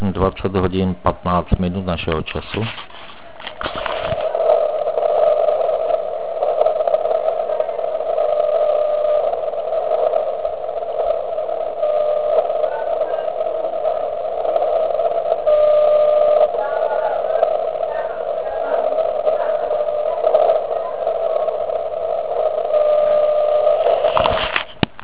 Vysílal zkušebně na 3536.1 KHz.
V Liberci: